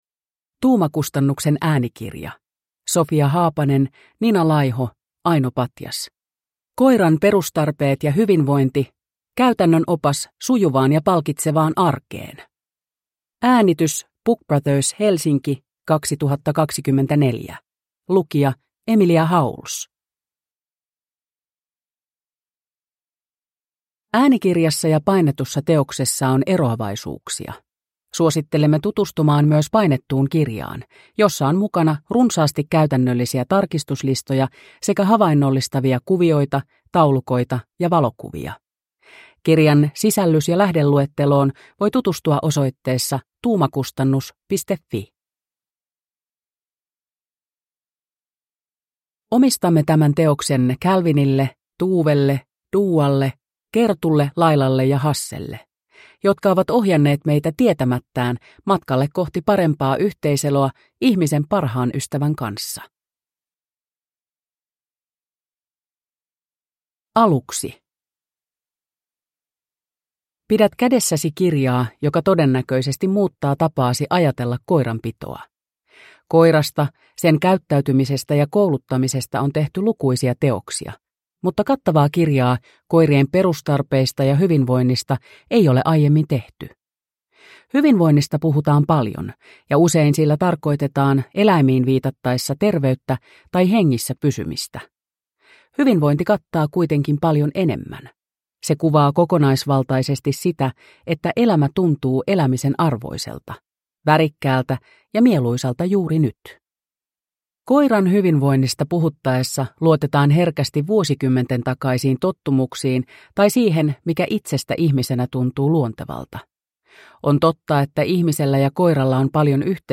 Koiran perustarpeet ja hyvinvointi – Ljudbok